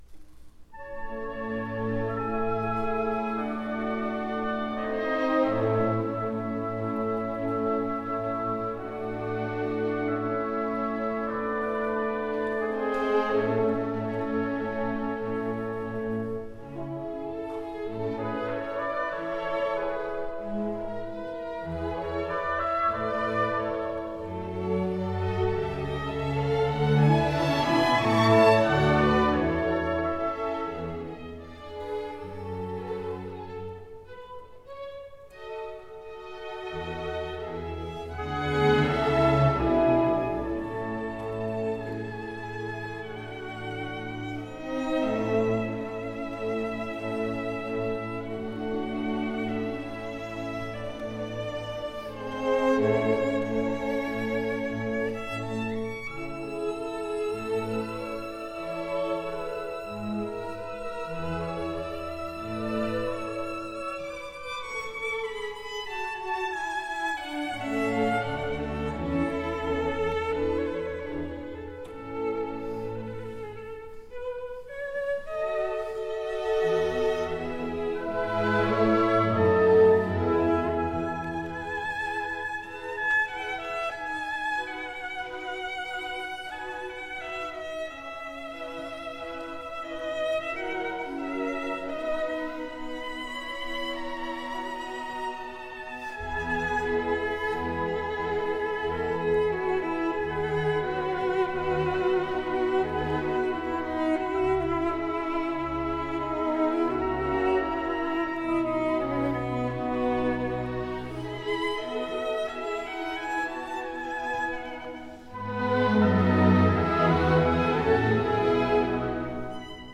violin
II Andante cantabile.